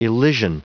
Prononciation du mot elysian en anglais (fichier audio)
Prononciation du mot : elysian